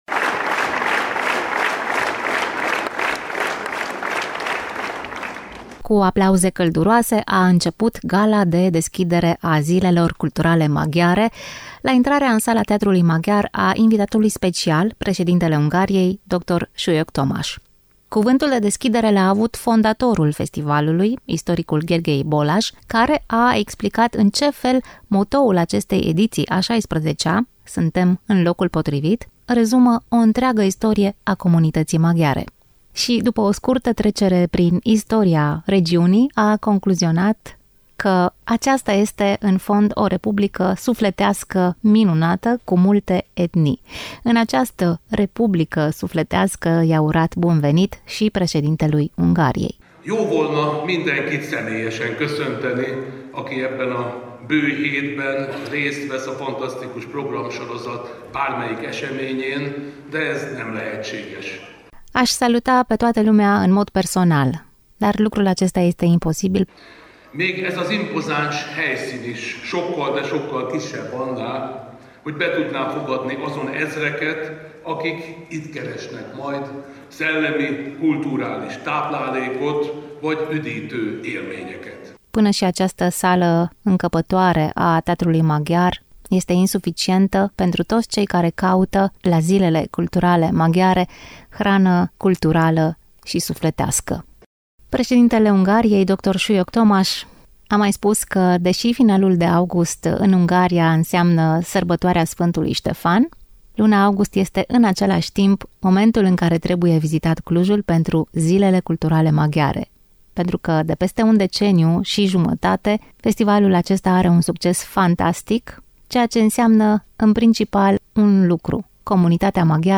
Invitat de onoare al evenimentului care s-a desfășurat la Teatrul Maghiar a fost președintele Ungariei, dr. Tamás Sulyok.